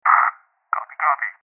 Megaphone.mp3